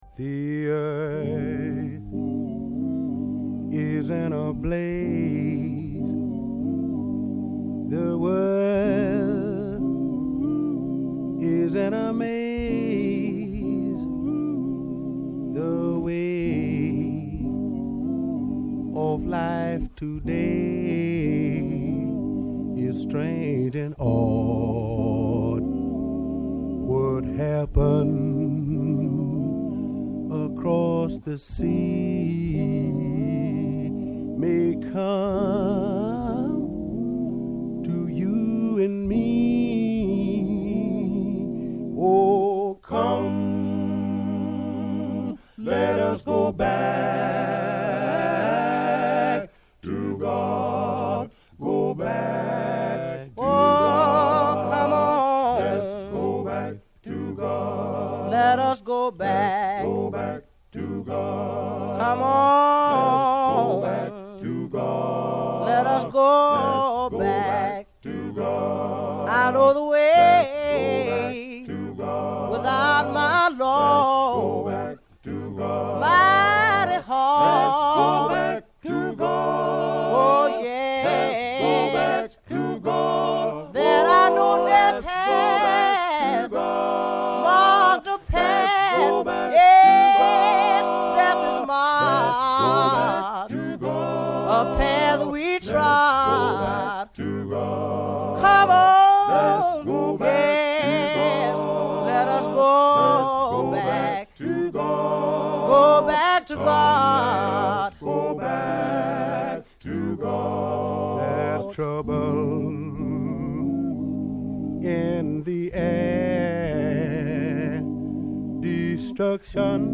Ακούμε το σάουντρακ